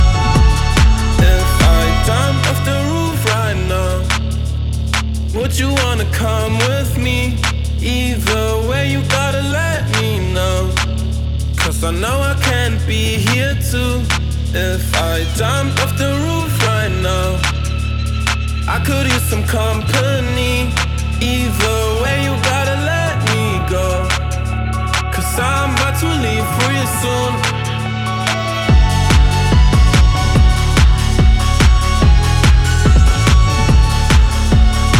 Скачать припев, мелодию нарезки
2021-11-05 Жанр: Поп музыка Длительность